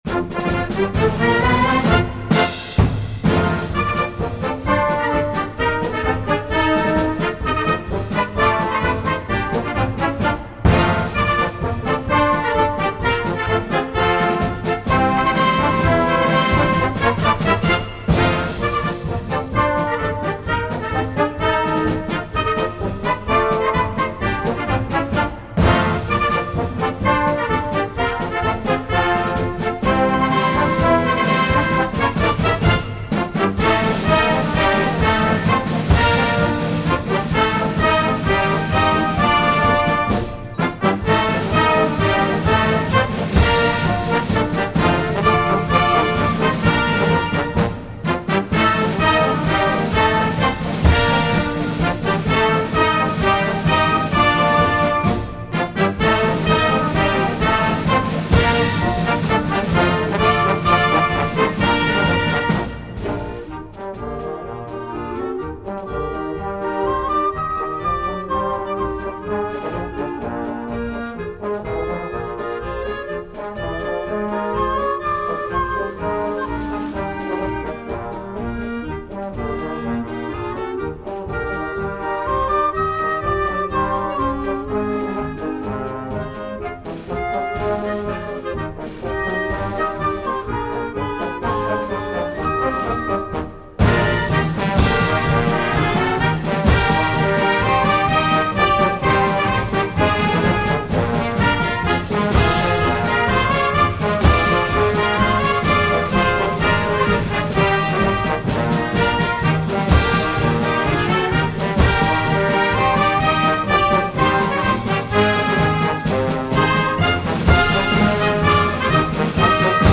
Gattung: March
Besetzung: Blasorchester